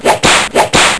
Zweep
ZWEEP.WAV